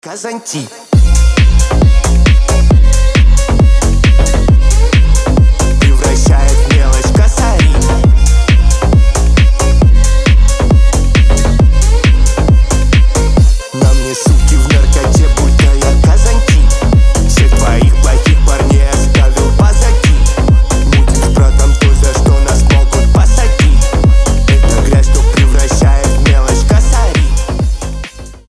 Euro House
рейв
Пацанский рейв